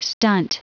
Prononciation du mot stunt en anglais (fichier audio)
Prononciation du mot : stunt